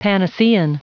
Prononciation du mot panacean en anglais (fichier audio)
Prononciation du mot : panacean